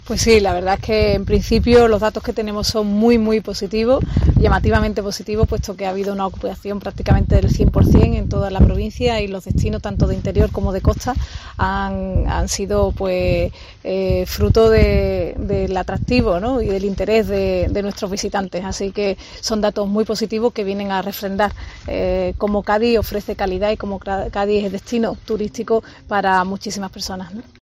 Escucha aquí a Ana Mestre, delegada en Cádiz del Gobierno de la Junta de Andalucía